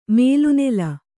♪ mēlu nela